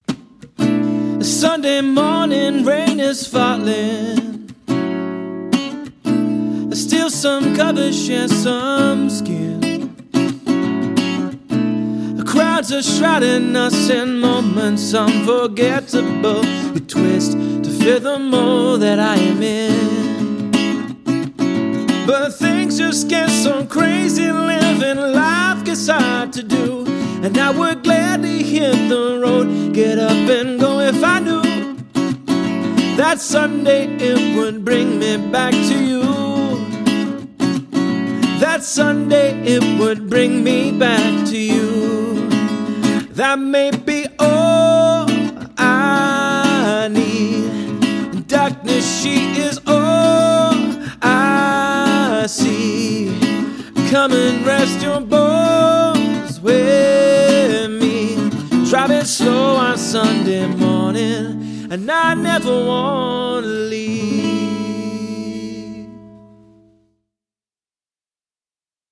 Soulful singer and skilled solo guitarist.
• Versatile performer covering jazz, soul, pop, and R&B.
Male Singer Guitarist